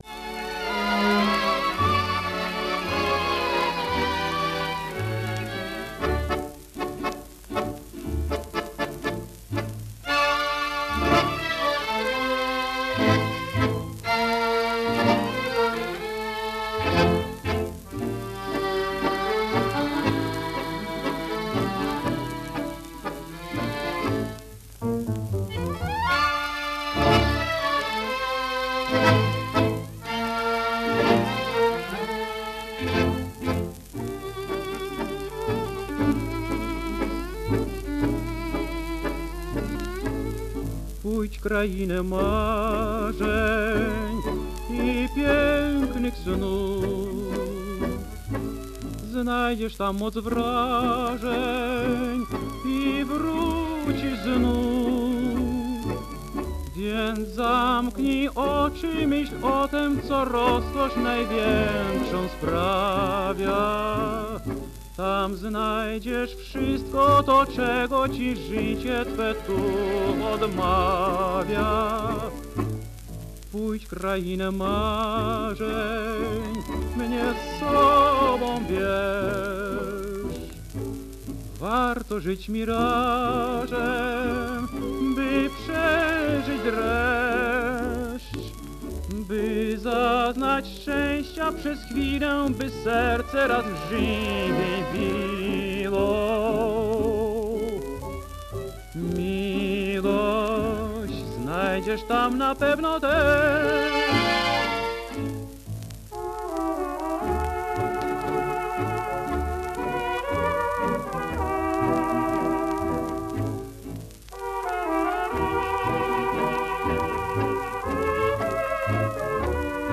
Wileńskie przetwory i gość-gawędziarz.